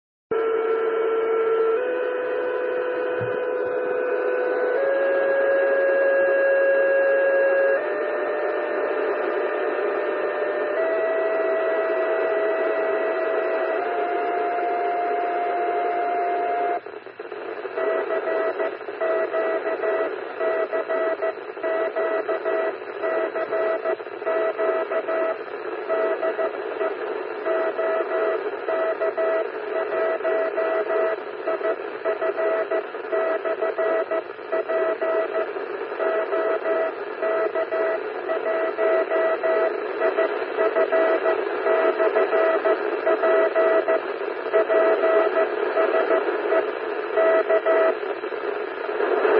Změnil jsem program a na začátku dávání výzvy jsem dal tóny od 450  Hz do 700 Hz po 50 Hz.
test tonu.mp3